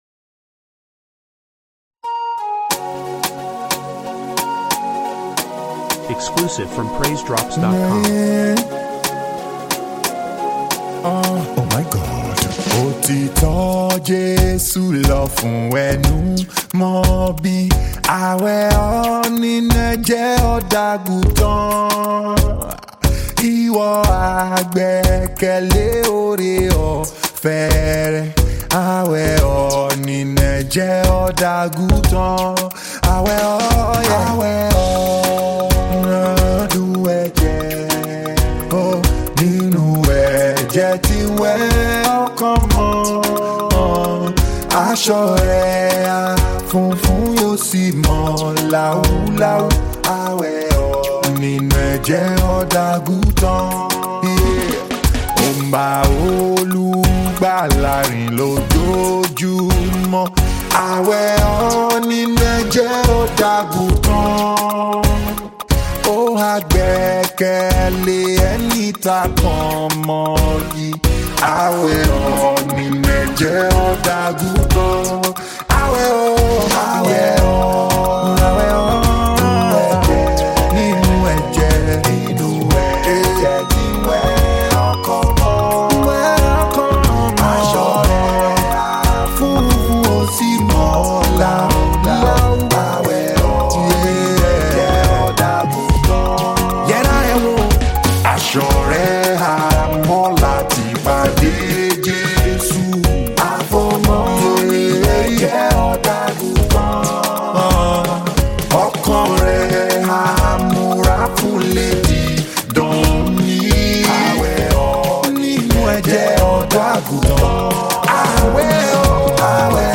Gospel Music
Soul Uplifting Nigerian Gospel song